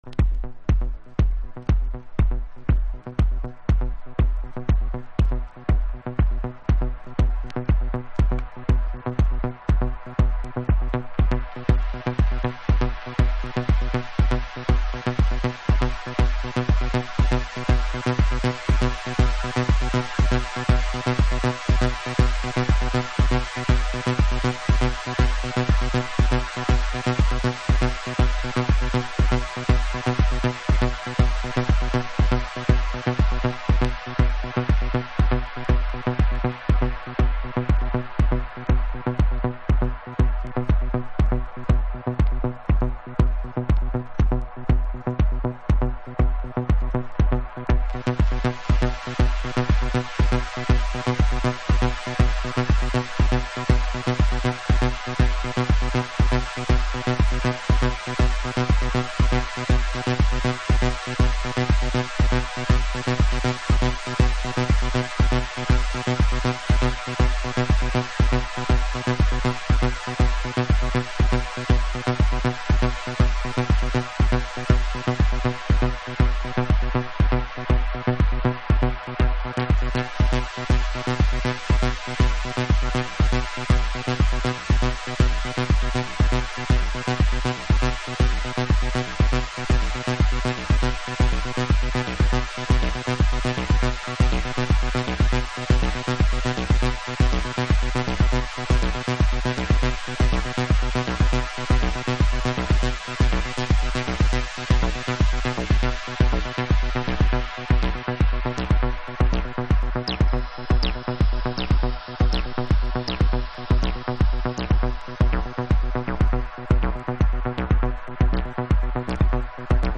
House / Techno
テン年代に入ってもプリセットスタイルは変わらず、音の定位が生々しく感じられ、独自のグルーヴを獲得しています。